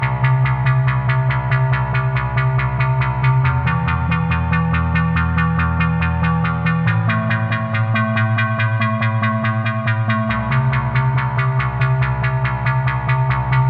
描述：键 C min 电子/房子合成器。
Tag: 126 bpm Electronic Loops Bass Synth Loops 952.43 KB wav Key : C